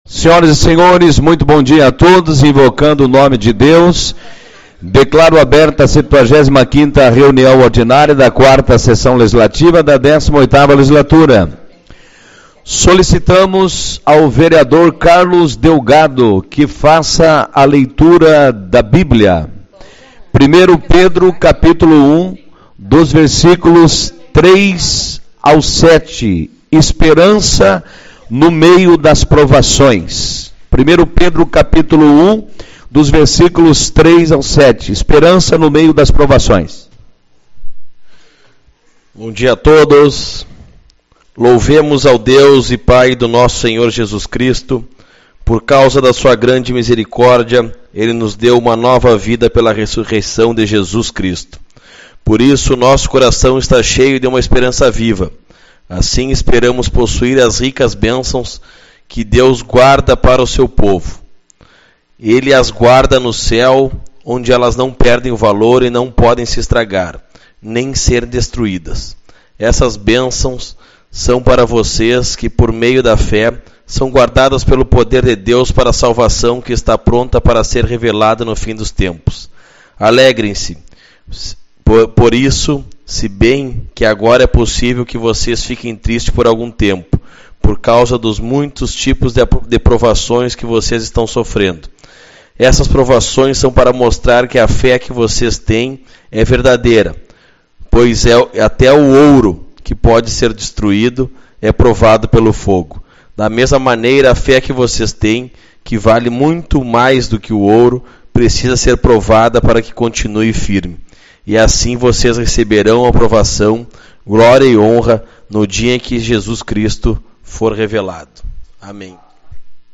12/11 - Reunião Ordinária